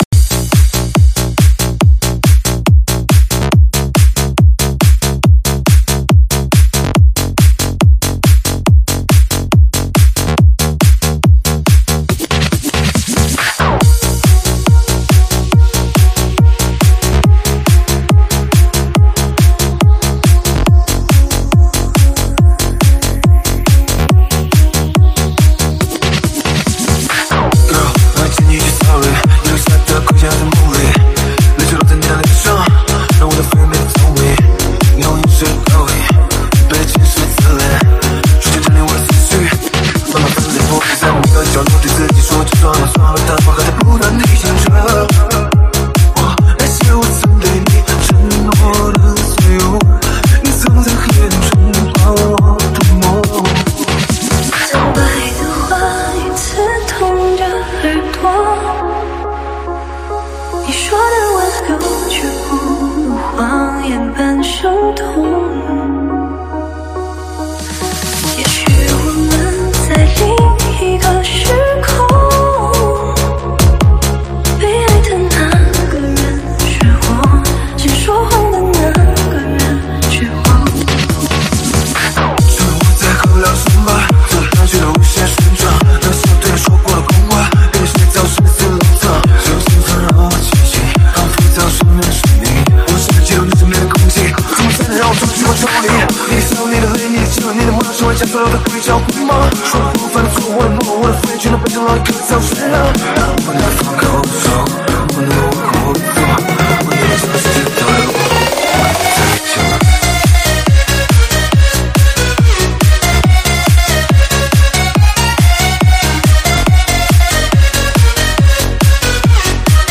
试听文件为低音质，下载后为无水印高音质文件 M币 4 超级会员 M币 2 购买下载 您当前未登录！